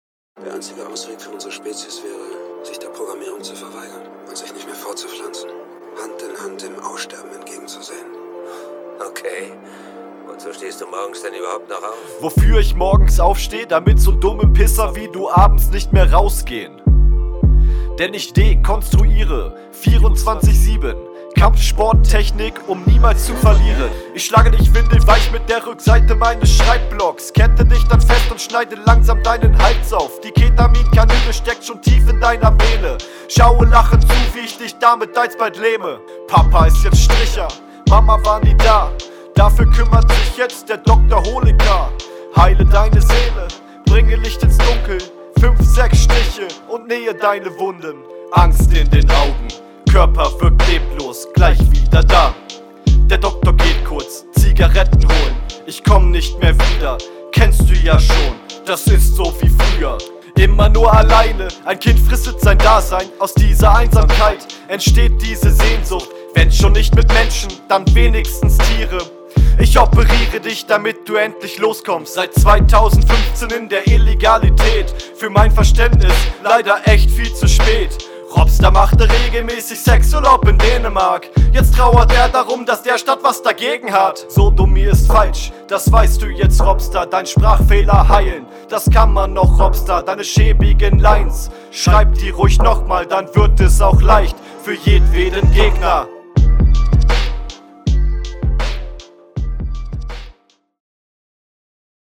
Ich checke wo du mit den Pausen im Flow hinwillst aber die sind manchmal echt …